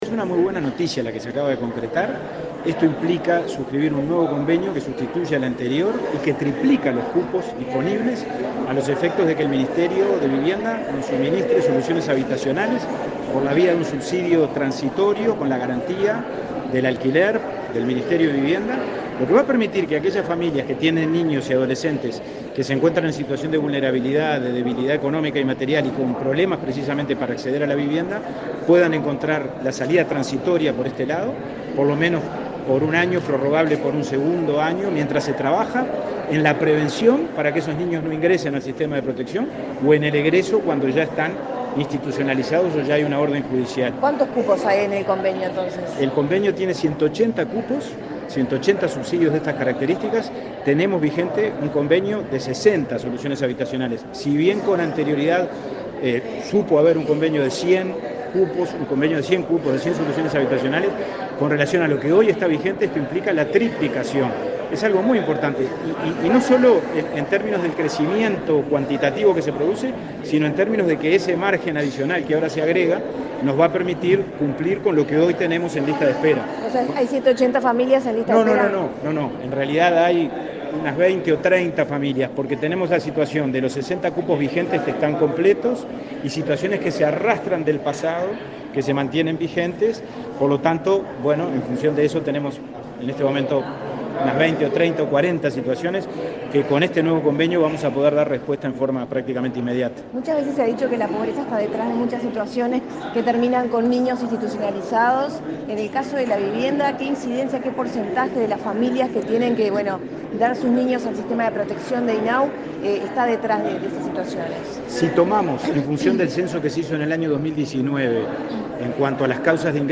Declaraciones del presidente de INAU, Pablo Abdala
Declaraciones del presidente de INAU, Pablo Abdala 06/09/2022 Compartir Facebook X Copiar enlace WhatsApp LinkedIn El Ministerio de Vivienda y el Instituto del Niño y Adolescente del Uruguay (INAU) firmaron un convenio para asegurar la continuidad del acuerdo que brinda soluciones transitorias de vivienda a familias. Luego, el presidente del instituto, Pablo Abdala, dialogó con la prensa.